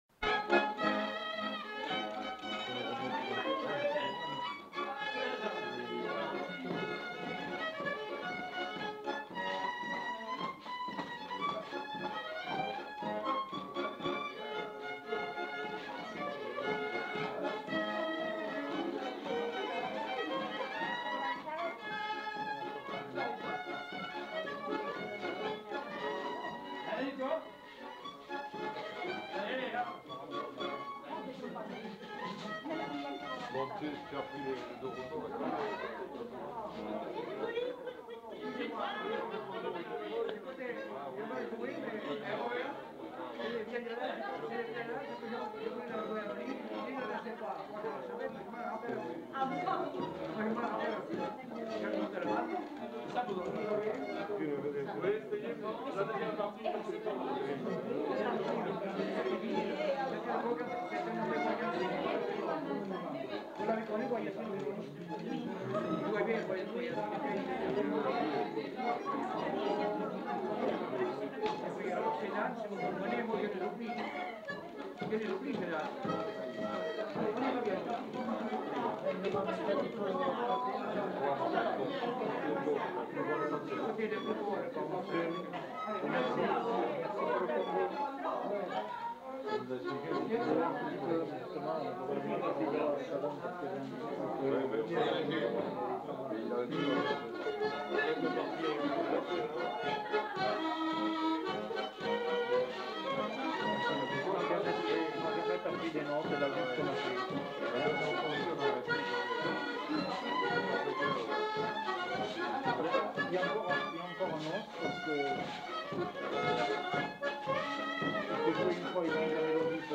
Rondeau
Lieu : Allons
Genre : morceau instrumental
Instrument de musique : violon ; accordéon diatonique
Danse : rondeau ; polka